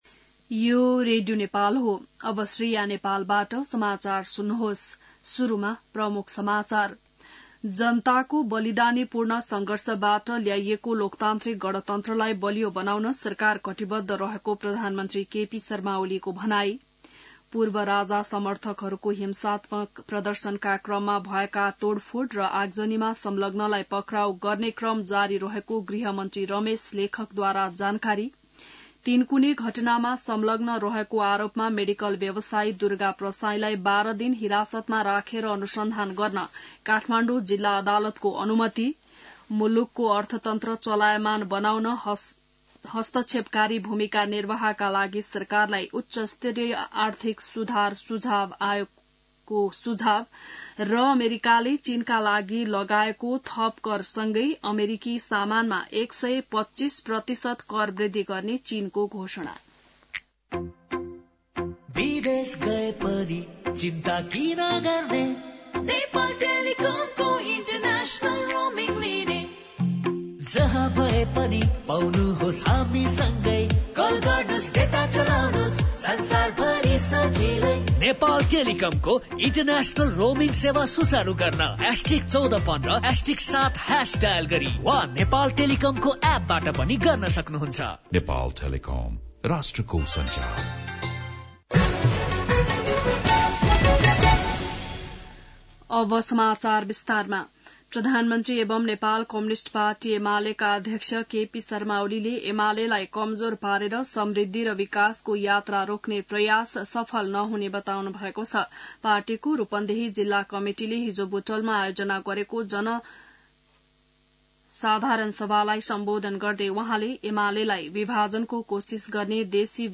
An online outlet of Nepal's national radio broadcaster
बिहान ७ बजेको नेपाली समाचार : ३० चैत , २०८१